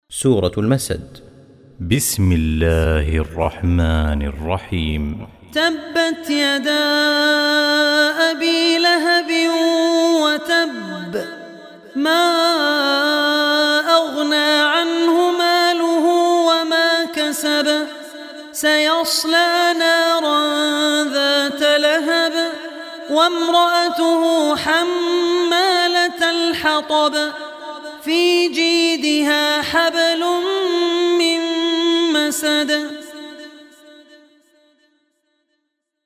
Surah Masad Recitation